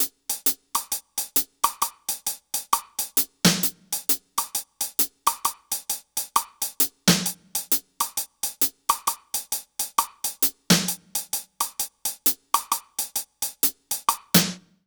British REGGAE Loop 132BPM (NO KICK) - 1.wav